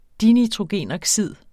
Udtale [ ˈdinitʁoˌgeˀnogˌsiðˀ ]